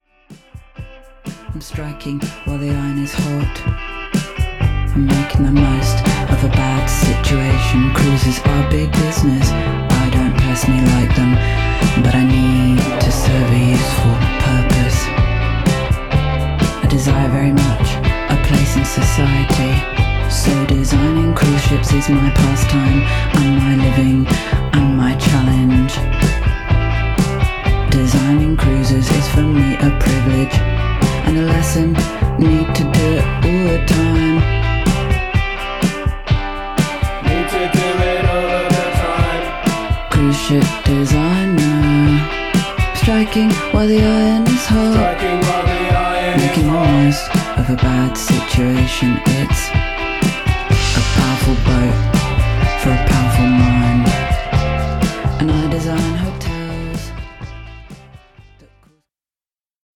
クールでスリリングなアルバムです。